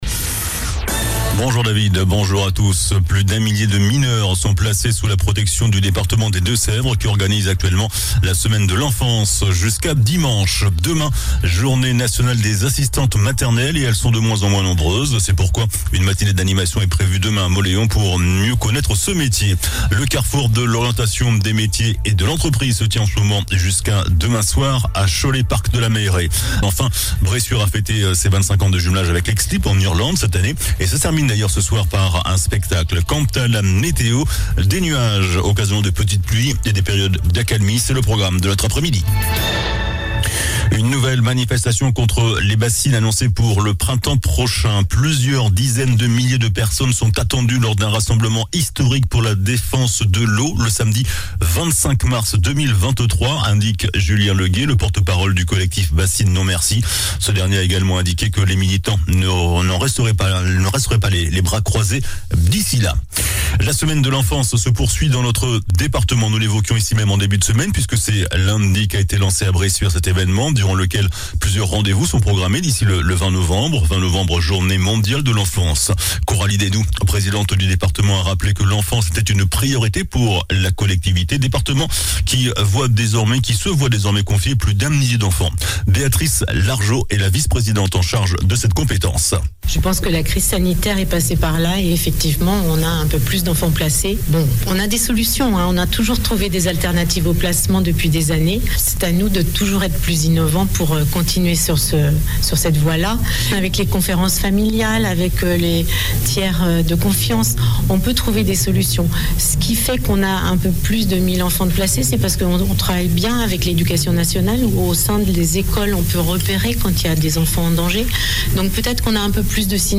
JOURNAL DU VENDREDI 18 NOVEMBRE ( MIDI )